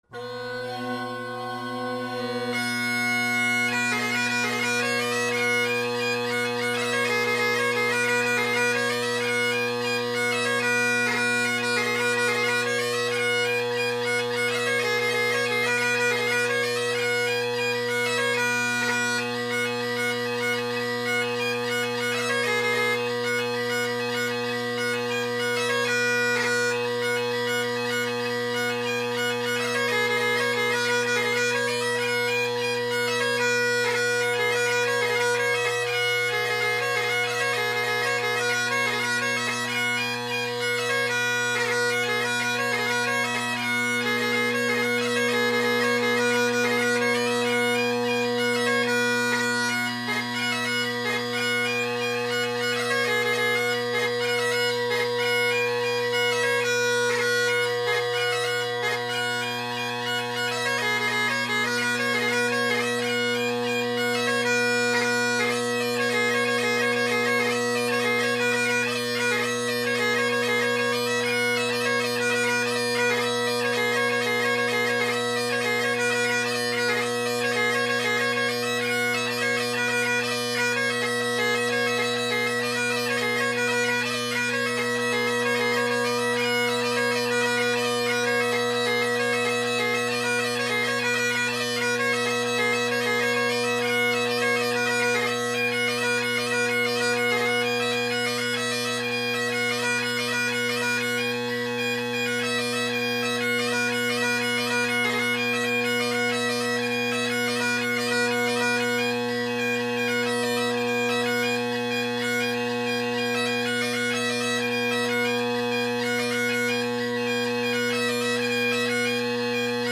Drone Sounds of the GHB, Great Highland Bagpipe Solo
I hope you enjoy listening in on my jams: